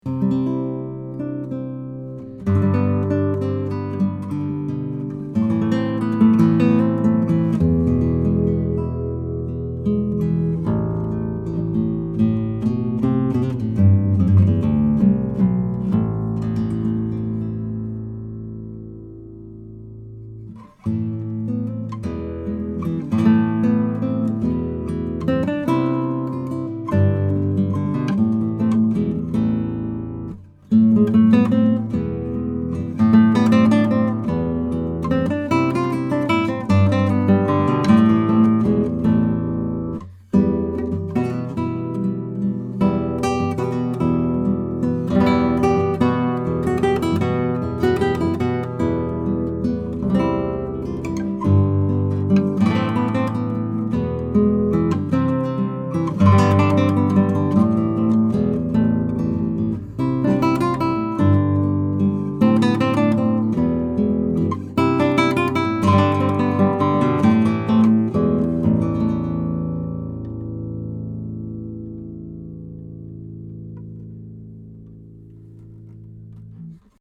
Here are 39 quick, 1-take MP3s of these mics into a Presonus ADL 600 preamp with a Rosetta 200 A/D converter. This is straight signal with no additional EQ or effects:
10-STRING CLASSICAL HARP GUITAR: